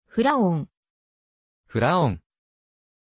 Pronunciation of Julián